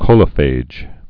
(kōlə-fāj)